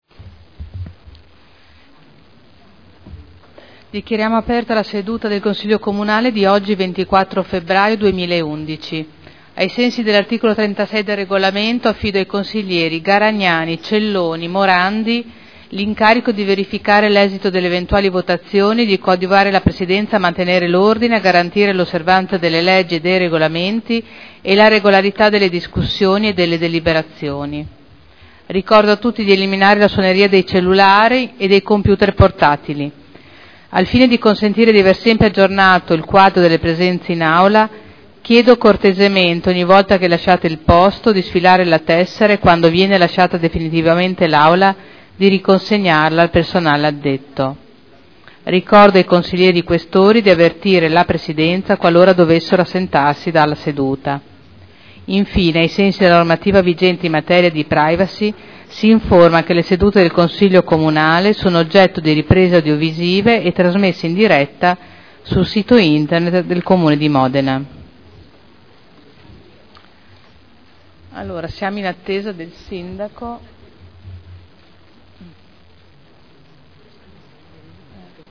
Seduta del 24/02/2011. Apre il Consiglio Comunale.